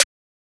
pluggnbsnare2.wav